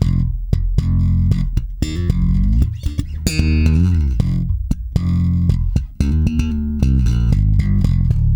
-JP THUMB F.wav